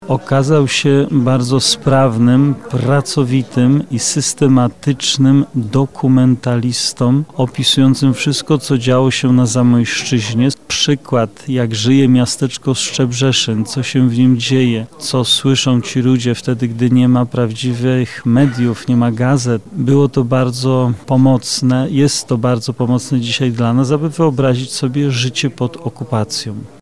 Konferencja odbyła się w Bibliotece Uniwersyteckiej KUL.